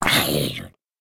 latest / assets / minecraft / sounds / mob / zombie / hurt2.ogg
hurt2.ogg